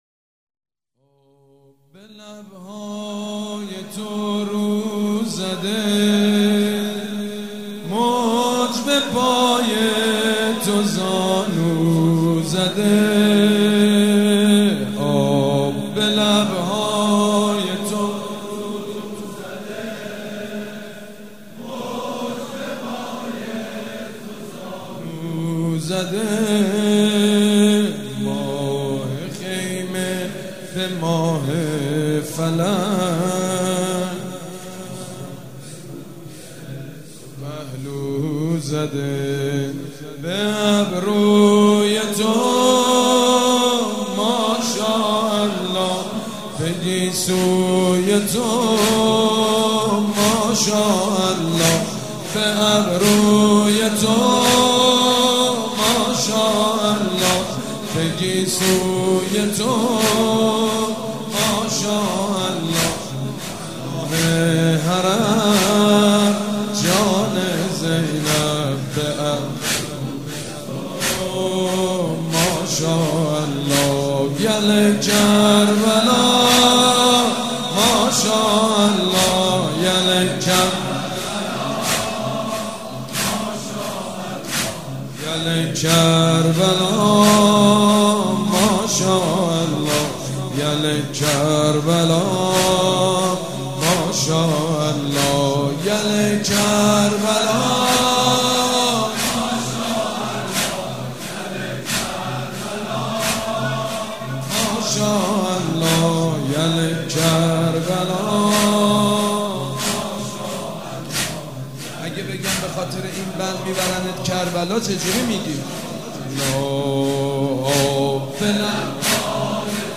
مراسم شهادت حضرت زینب کبری(سلام الله عليها)